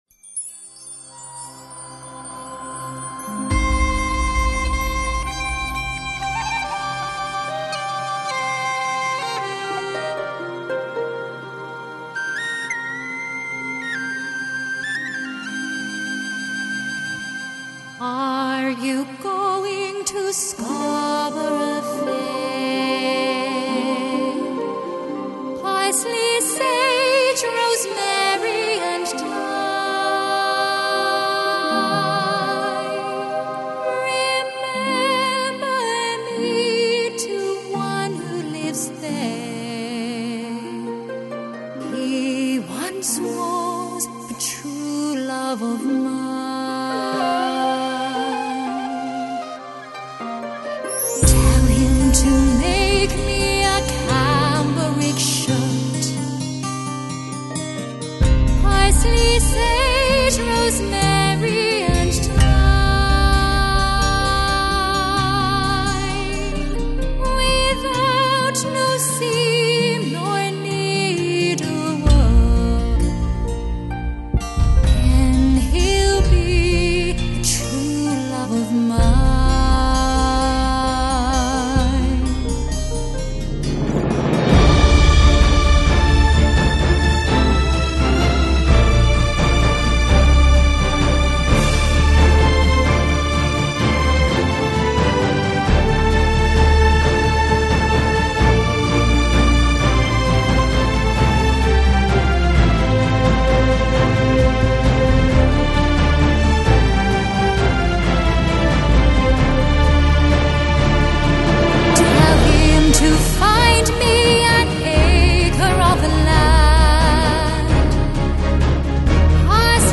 Жанр: Folk, Celtic, Singer-Songwriter
它的声音突出了音乐的纯洁和美丽。